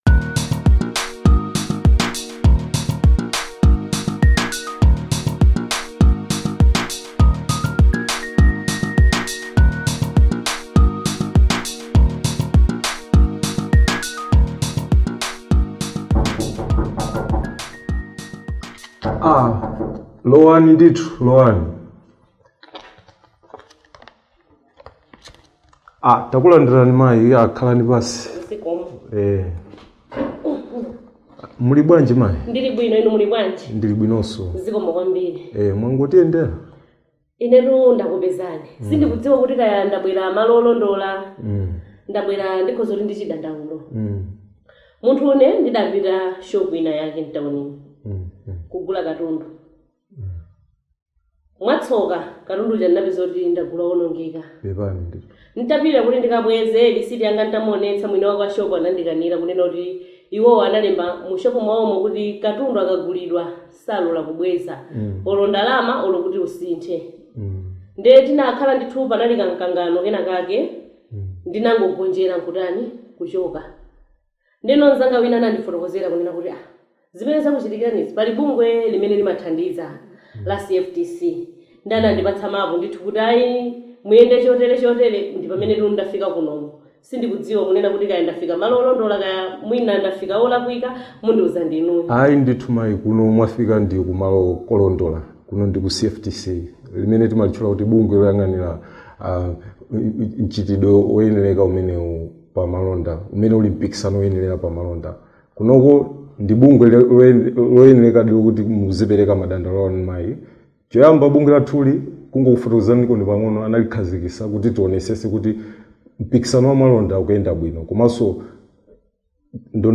Radio Comedy 4- Registering a Complaint